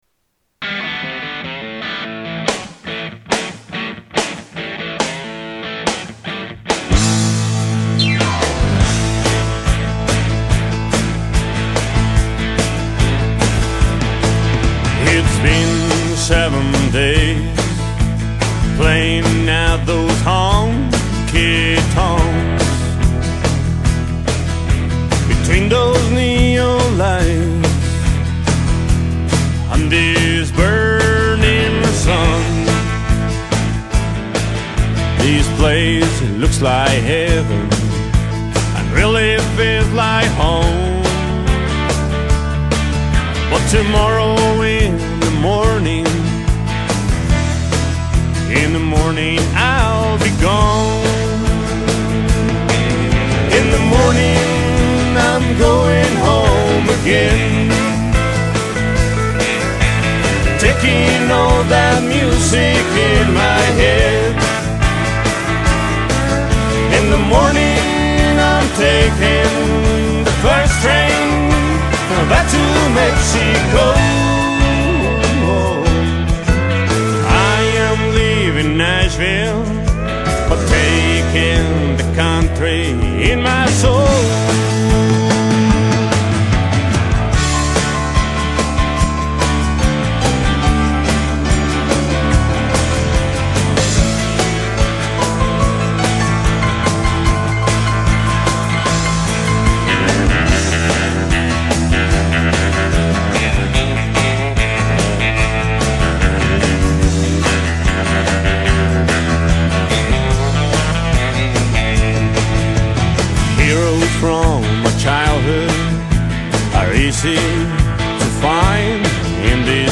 Acoustic Guitar & Lead Vocals
Electric Guitar, Backing vocals
Drum
Bass